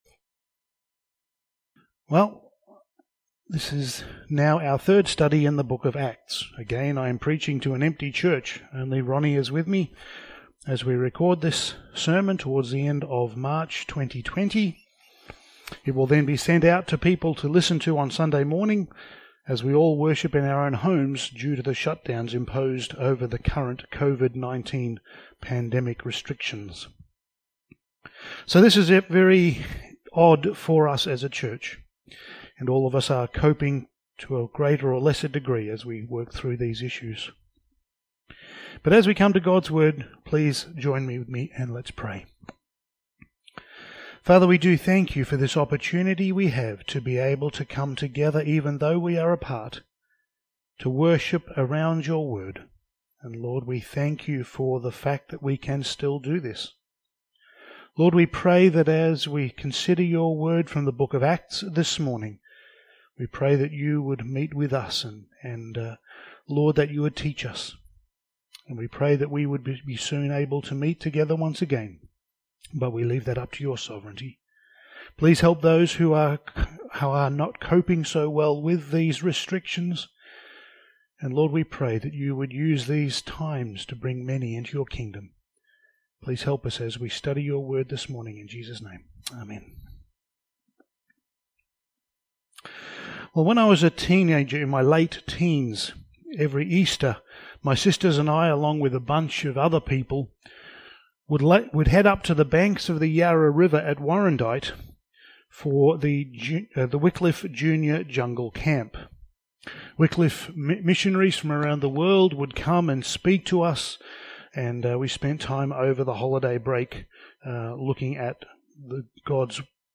Passage: Acts 1:12-26 Service Type: Sunday Morning